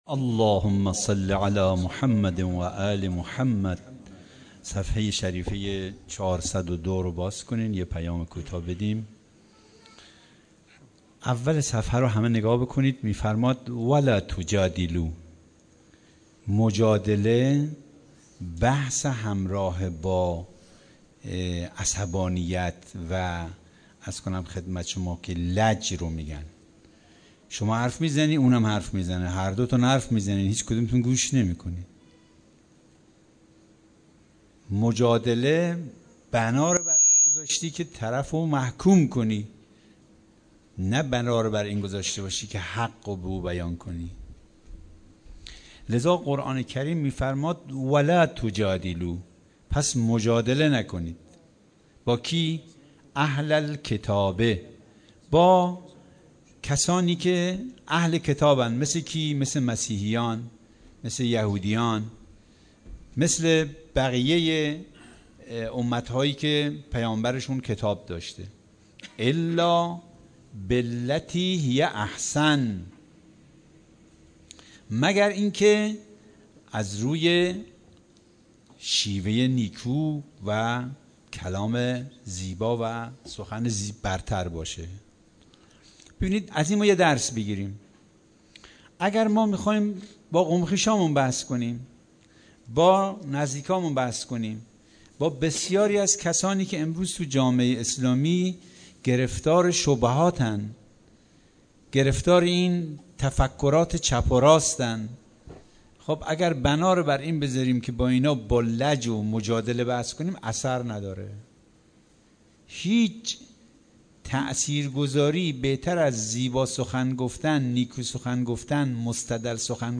گزارش صوتی دویست و پنجاه و یکمین کرسی تلاوت و تفسیر قرآن کریم - پایگاه اطلاع رسانی ضیافت نور